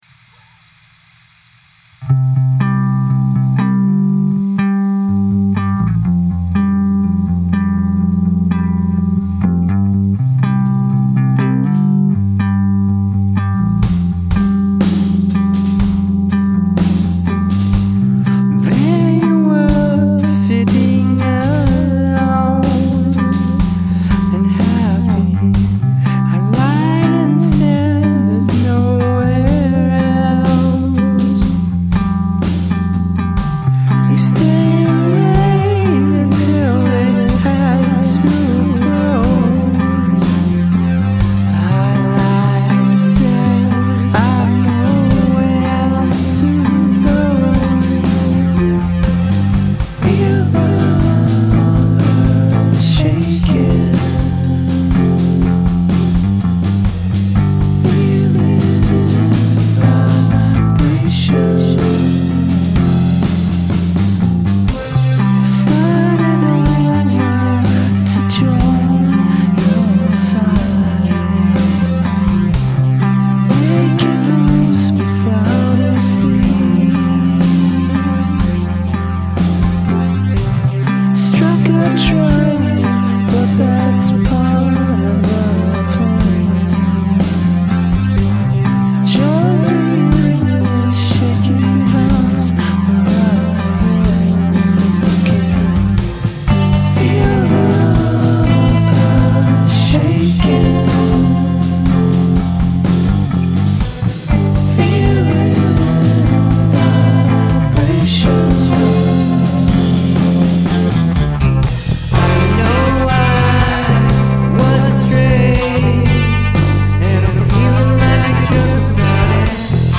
Singing seems uncertain during the verses.